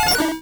Cri de Grodoudou dans Pokémon Rouge et Bleu.